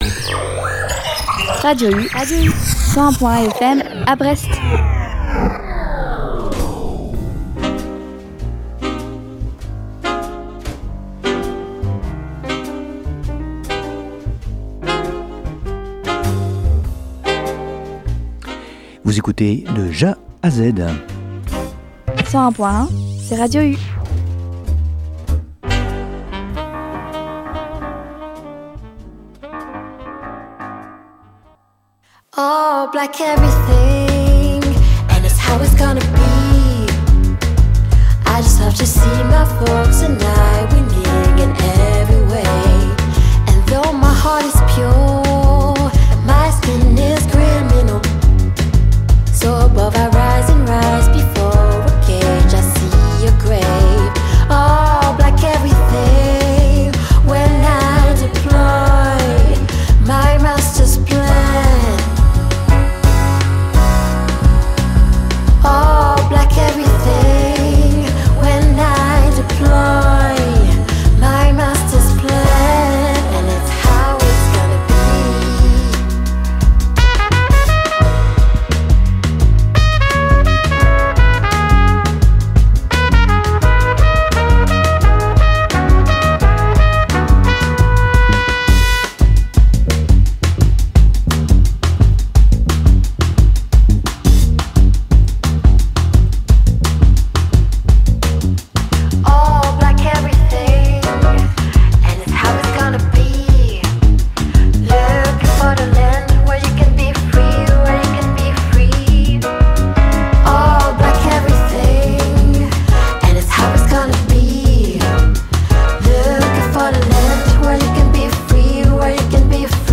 Retrouvez ma sélection jazz